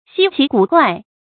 希奇古怪 注音： ㄒㄧ ㄑㄧˊ ㄍㄨˇ ㄍㄨㄞˋ 讀音讀法： 意思解釋： 奇怪而罕見。